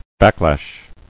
[back·lash]